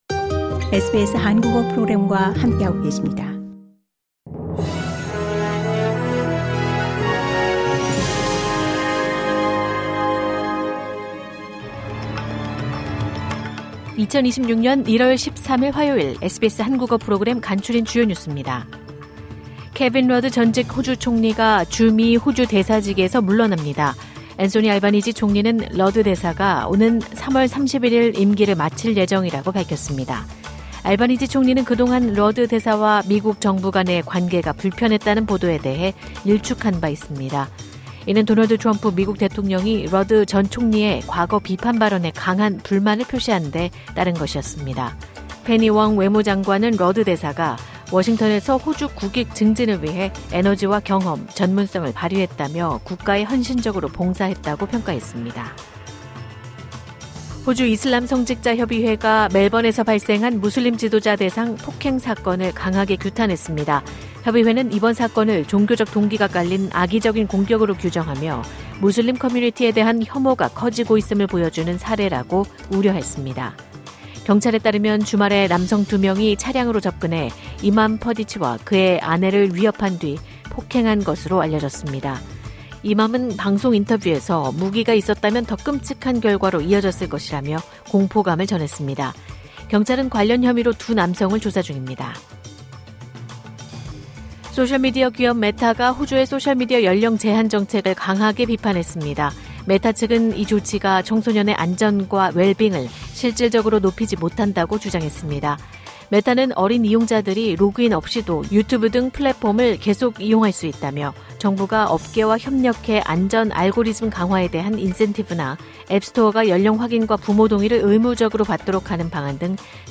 호주 뉴스 3분 브리핑: 2026년 1월 13일 화요일